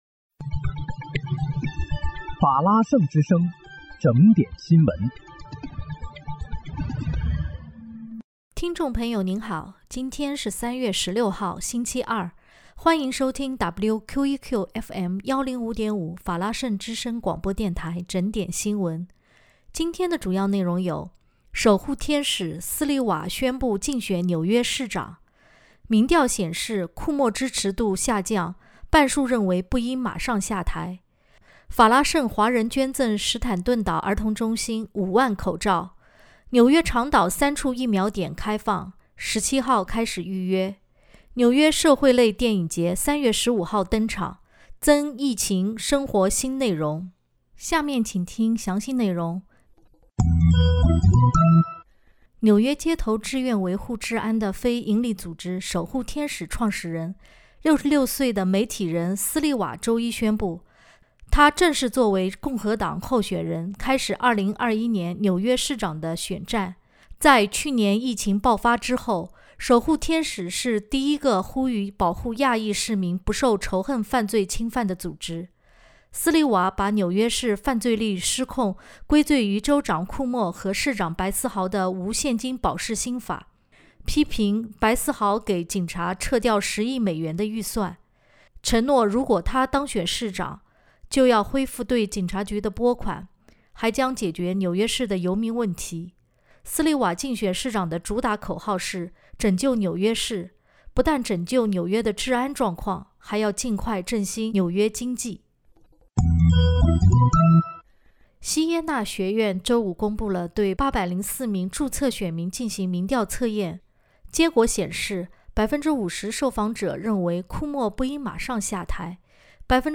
3月16日（星期二）纽约整点新闻